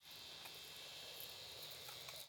Camera_ZoomIn.wav